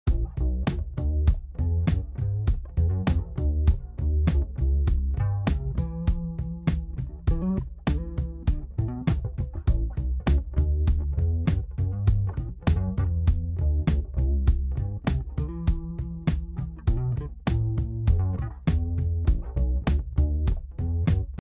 A Sad Groove For The Sound Effects Free Download